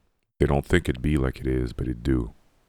Tags: Gen Z Deep Voice Yikes